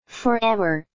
英音 (uk)